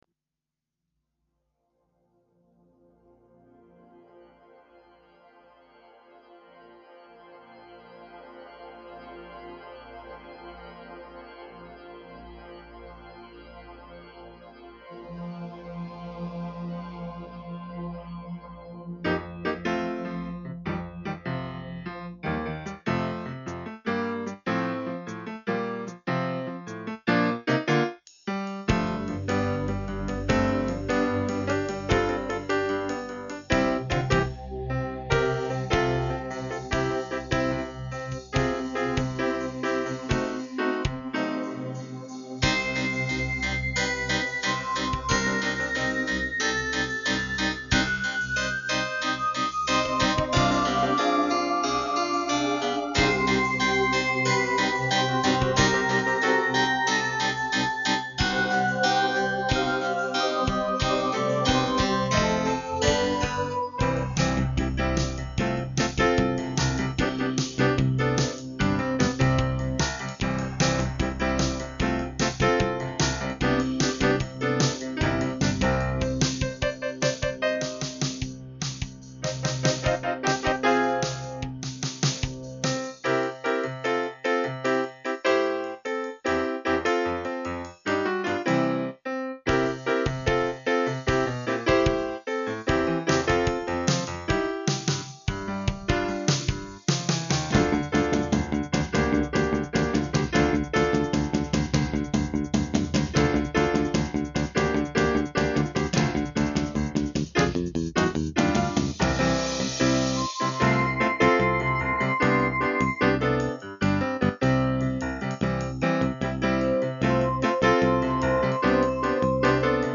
Lieder Playback